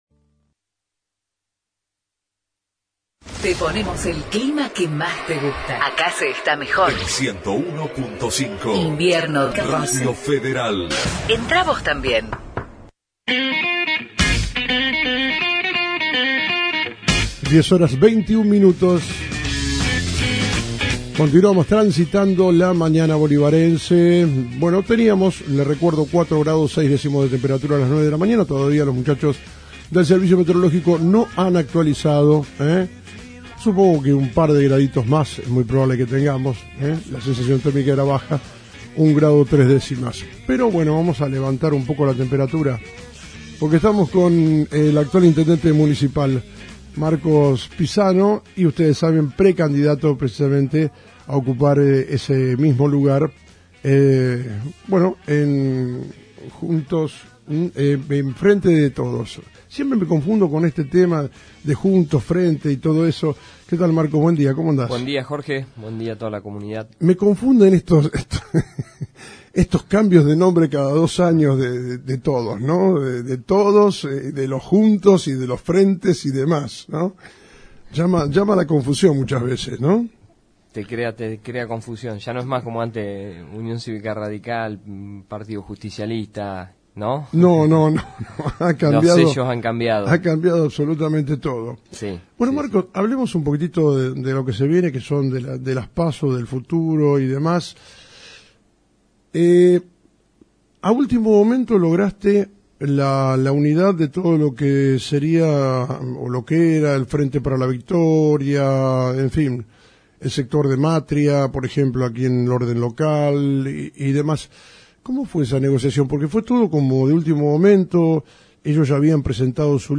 Entrevista al actual Intendente Marcos Pisano Precandidato a Intendente de Frente de Todos